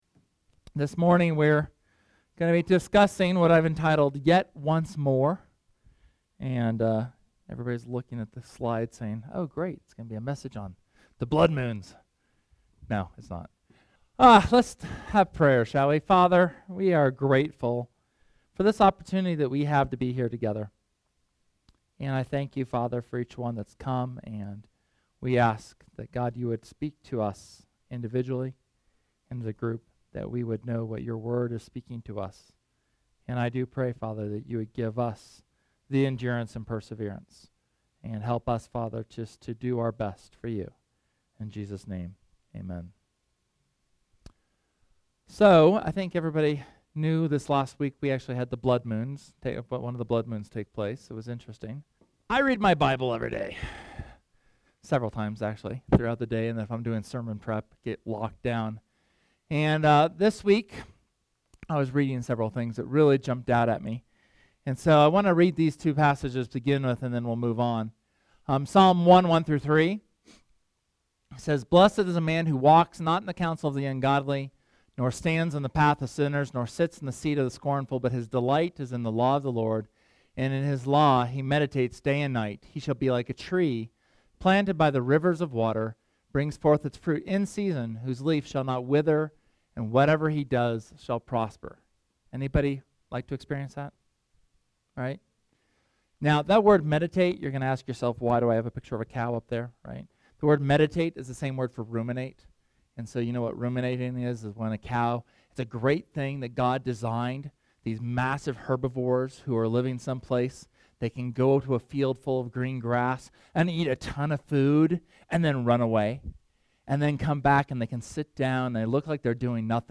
SERMON: Yet once more…